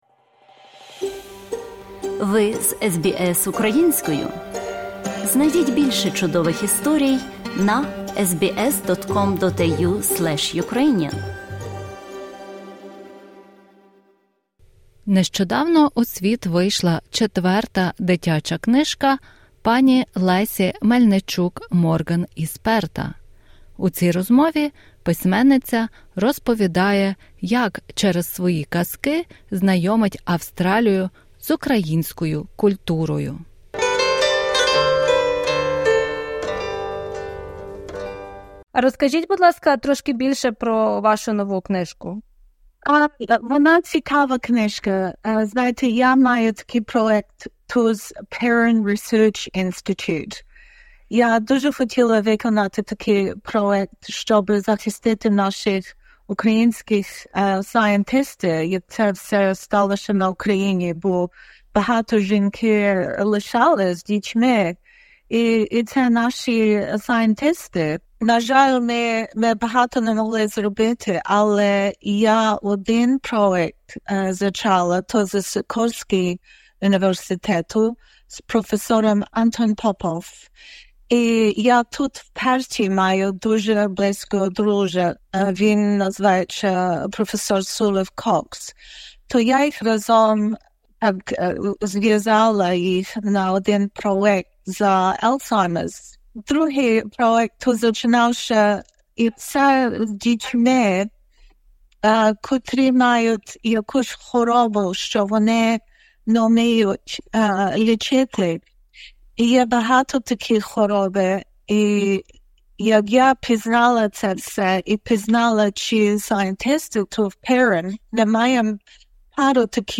У цій розмові письменниця розповідає, як через свої казки знайомить Австралію з українською культурою.